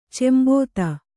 ♪ cembōta